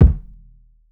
Sleep Kick.wav